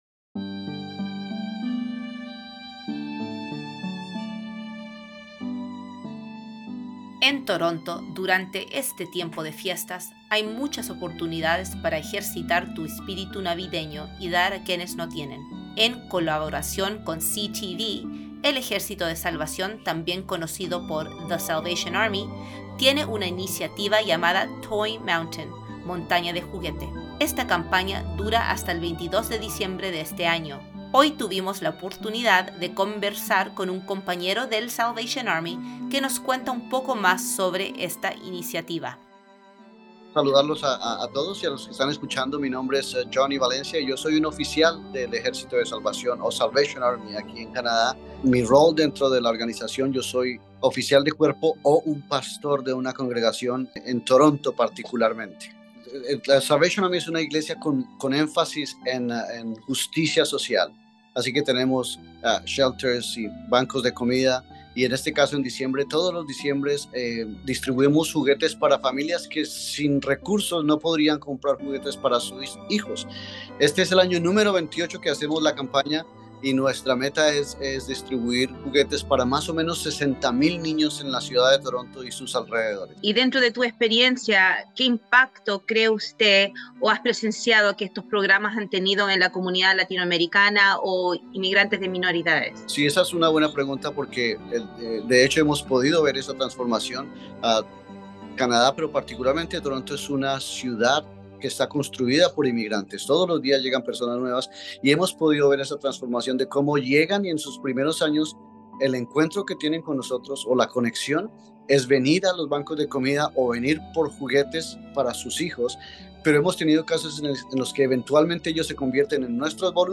En esta ocasión, tuvimos la oportunidad de conversar con el compañero